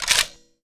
svd_boltback.wav